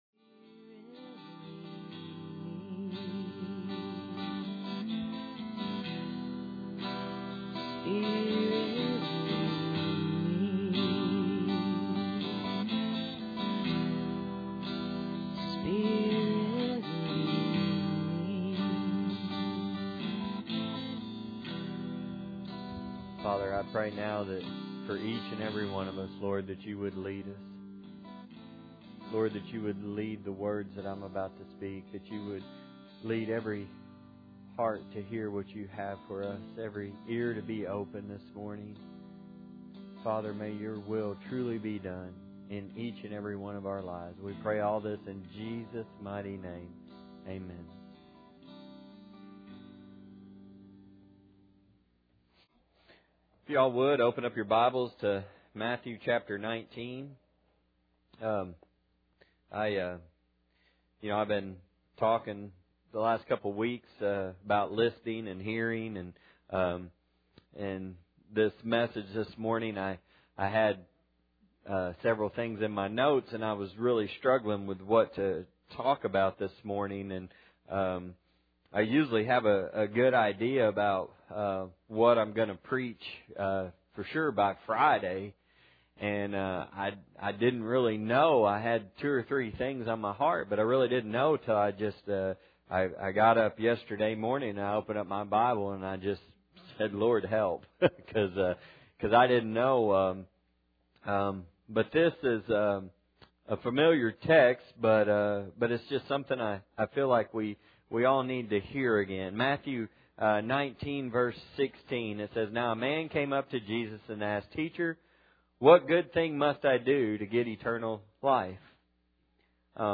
Matthew 19:16- Service Type: Sunday Morning Bible Text